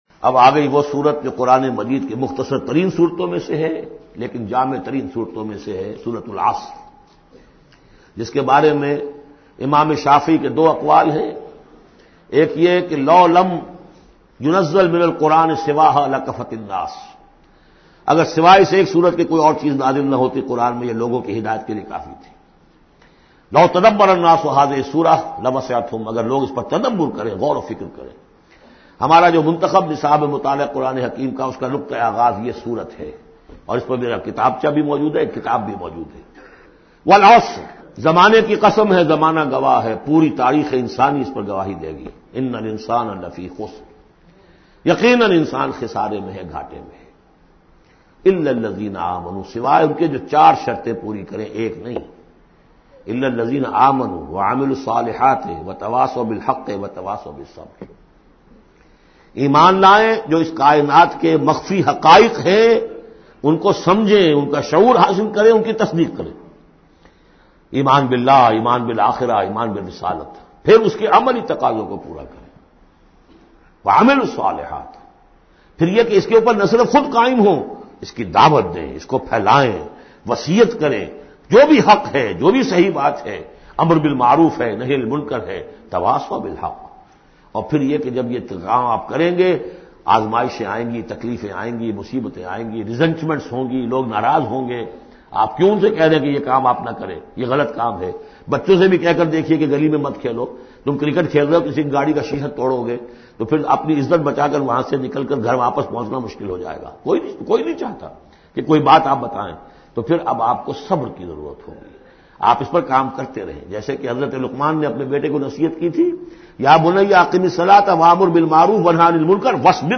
Surah Asr, listen online mp3 urdu tafseer in the voice of Dr Israr Ahmed.